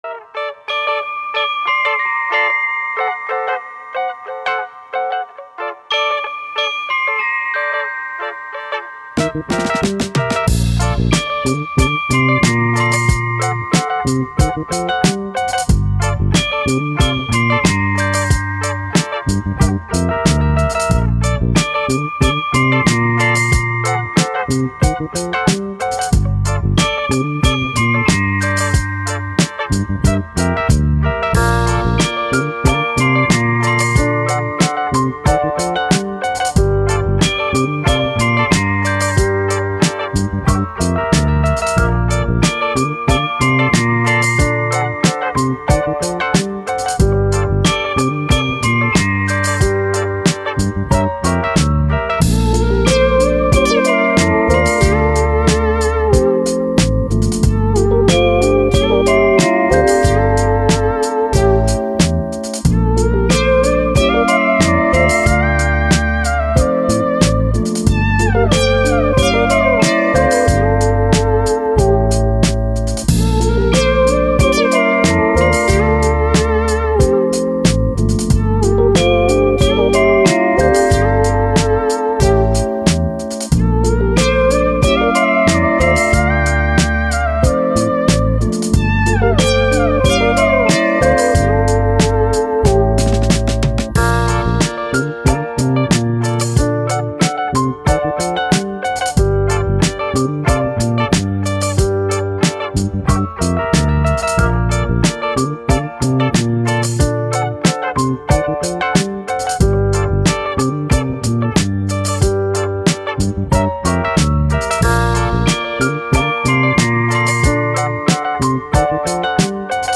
Soul, Vintage, Vibe, Positive